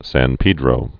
(săn pēdrō)